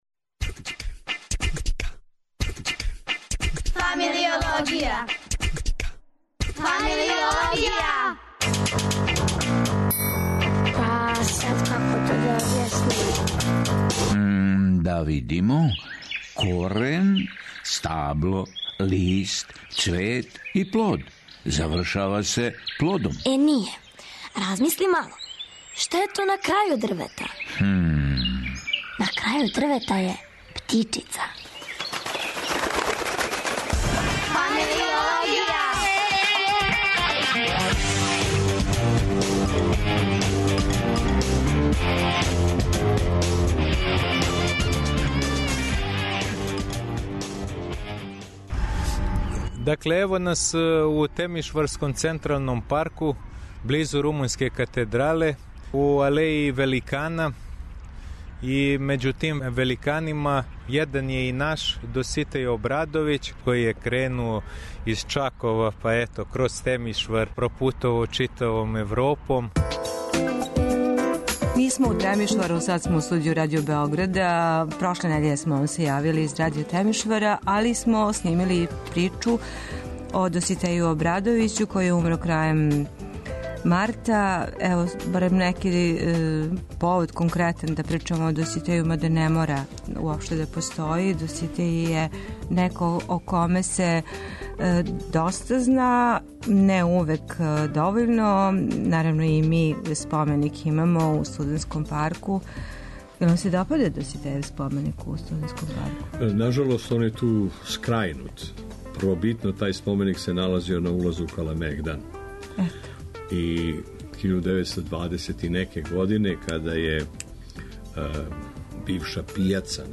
А, данас, у Фамилиологији нудимо вам и Породичну причу Доситеја Обрадовића снимљену у Чакову, његовом родном месту и Темишвару.